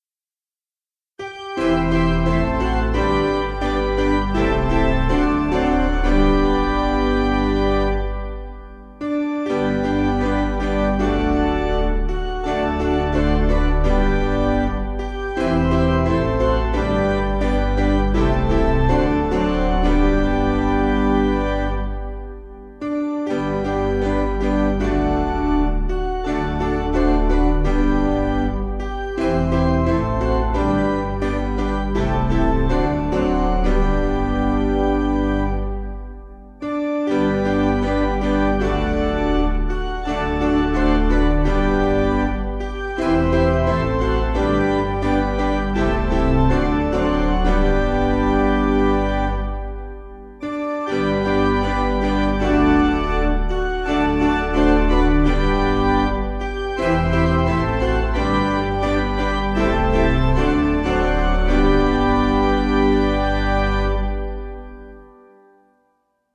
Key: G Major